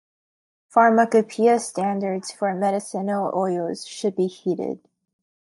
Pronounced as (IPA) /ˈhiːdɪd/